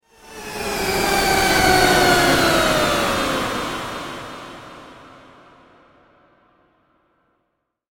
Cinematic Alien Spaceship Transition Sound Effect
This alien spaceship flyby transition sound effect delivers a powerful sci-fi whoosh with futuristic motion and metallic energy.
The high-quality audio adds impact, smooth movement, and a cinematic futuristic atmosphere that meets modern production standards.
Cinematic-alien-spaceship-transition-sound-effect.mp3